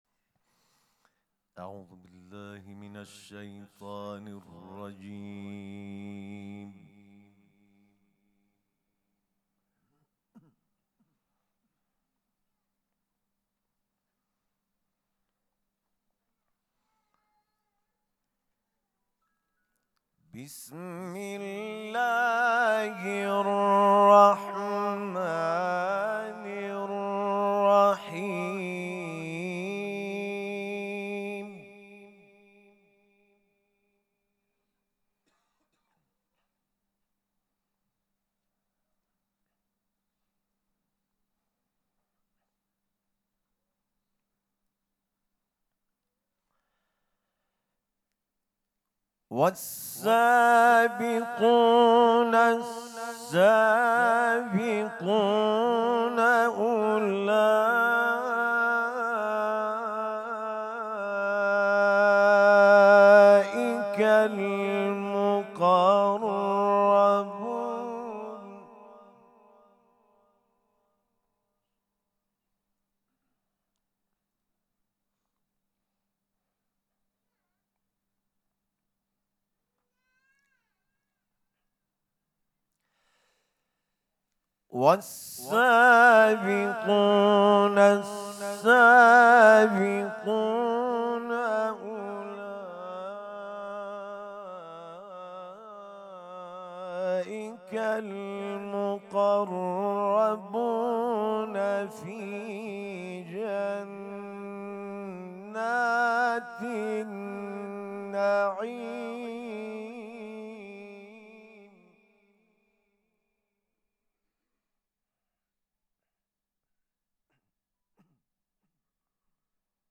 قرائت قرآن کریم
مراسم سوگواری شهادت حضرت زینب سلام الله علیها
سبک اثــر قرائت قرآن